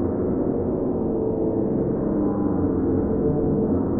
Index of /musicradar/sparse-soundscape-samples/Sample n Hold Verb Loops